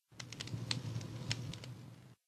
Minecraft Version Minecraft Version latest Latest Release | Latest Snapshot latest / assets / minecraft / sounds / block / campfire / crackle4.ogg Compare With Compare With Latest Release | Latest Snapshot
crackle4.ogg